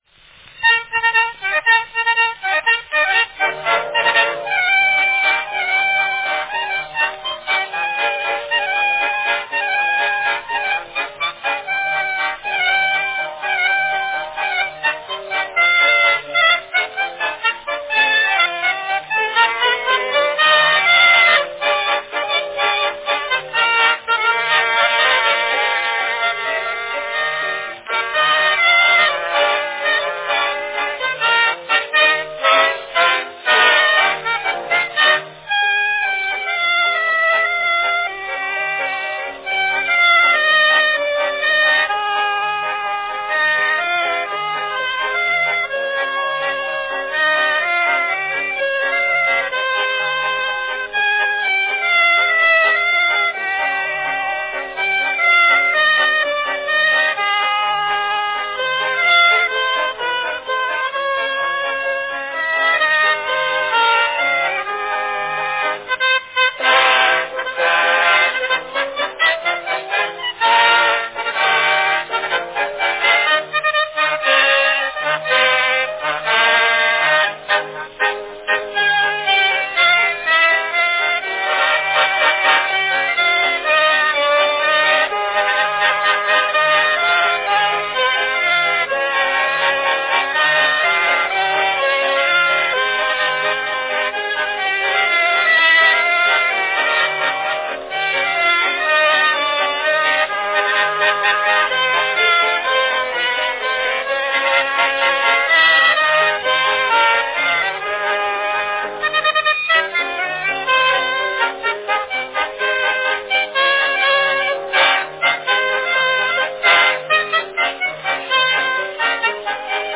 a rare early concertina recording from 1910
Category Concertina
Announcement None
2-minute wax cylinder recordings of the concertina